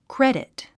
発音
krédit　クレディット